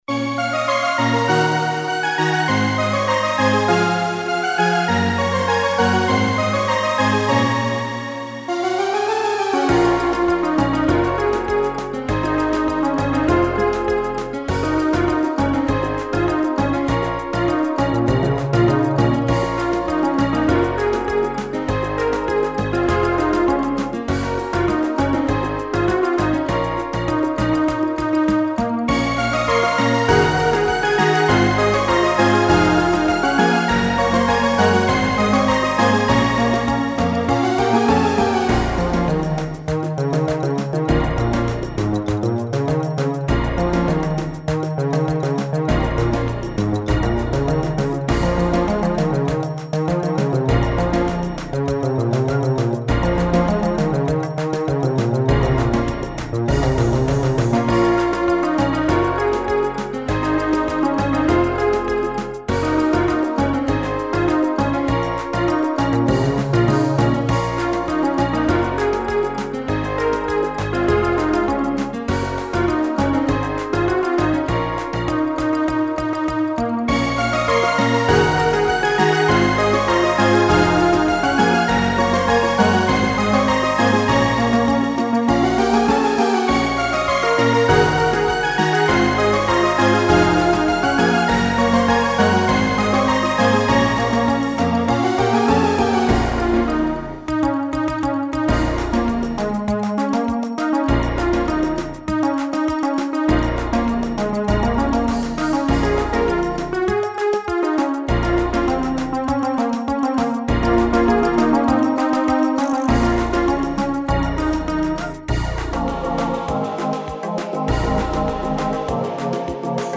Arranged as Sunday Song.